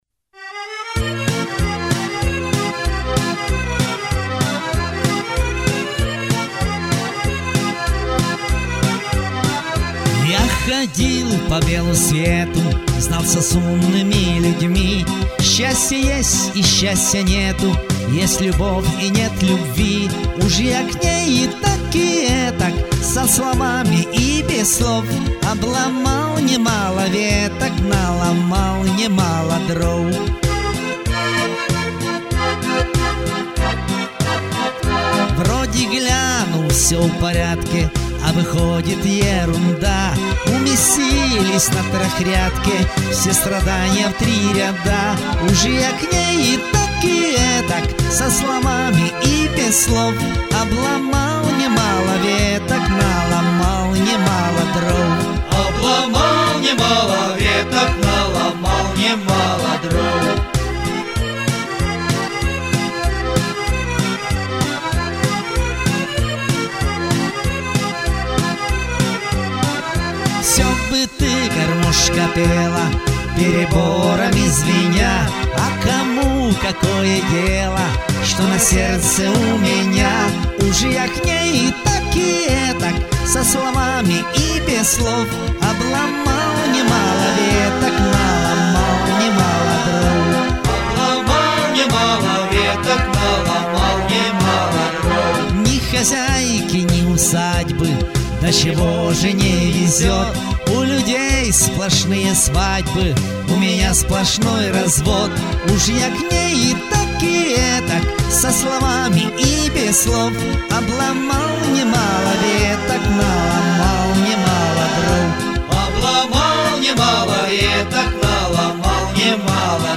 многоголосие